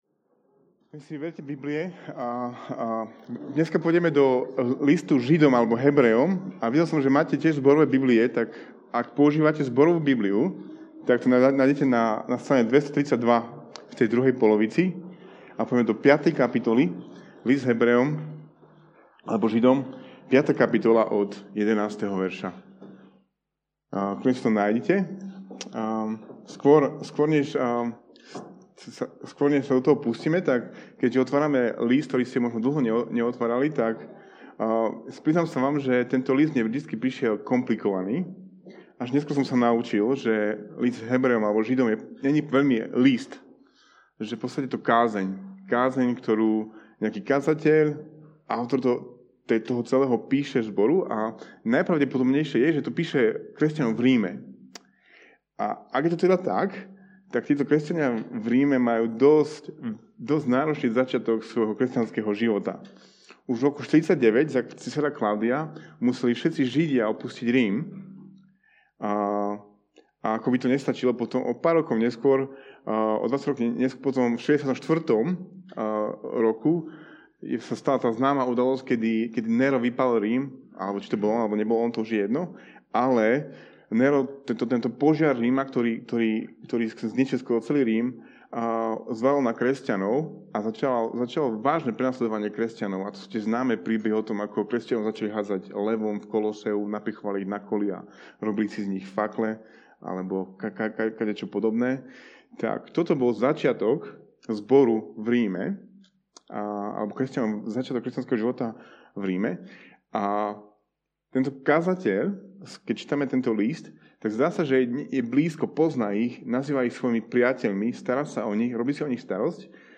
Podcasty Kázne zboru CB Trnava Pozor na (ne)počúvanie!